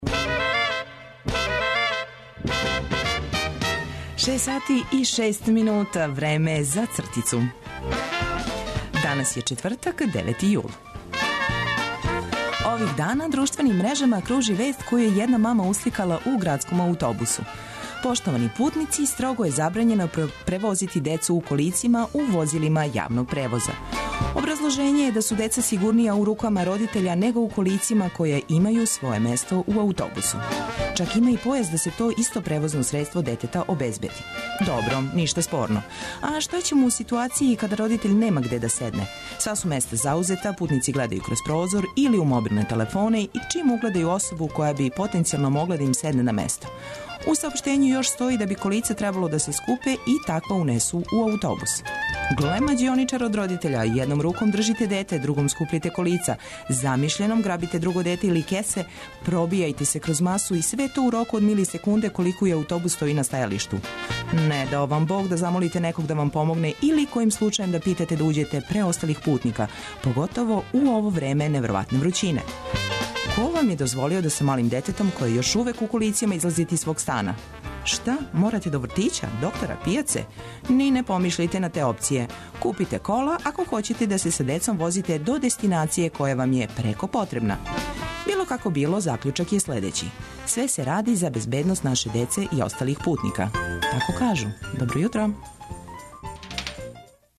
Док се лагано будите, ми смо ту да Вас информишемо о свим дешавањима. Цртица ће Вам пожелети добро јутро, графити измамити осмех, а сервисне, културне и спортске информације, прошаране добром музиком улепшати дан.